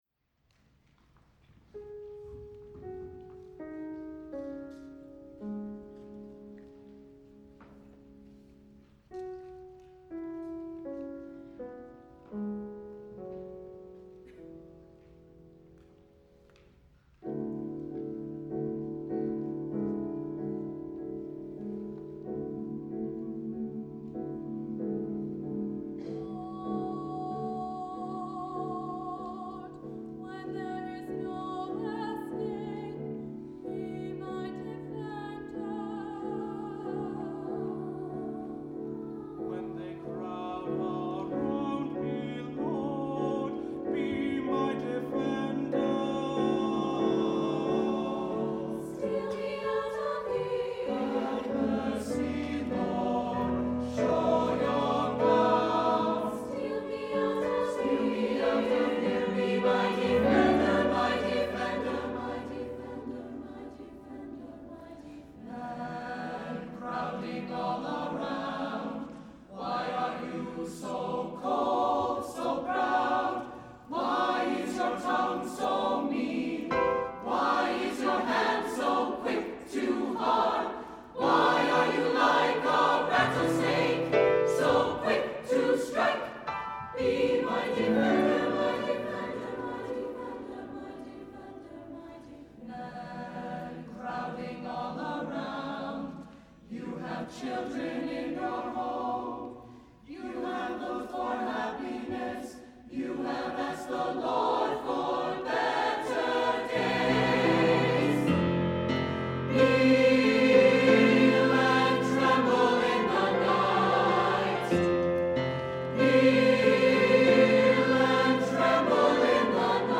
for SATB Chorus and Piano (2006)